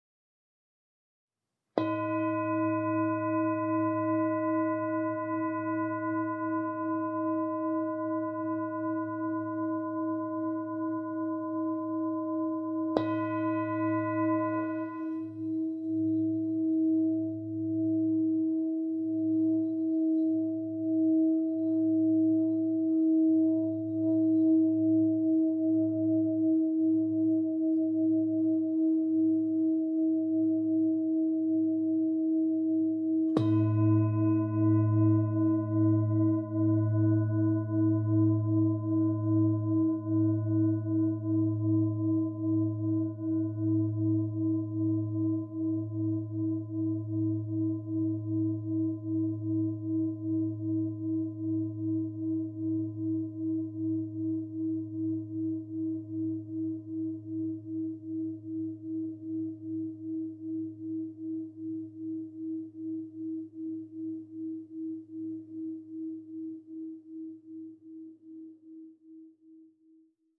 Meinl Sonic Energy Energy Therapy Series Singing Bowl - 1800g (SB-E-1800)
The Meinl Sonic Energy Energy Series Singing Bowls feature a reinforcement rim that produces a high tone at first followed by a deep tone afterward,…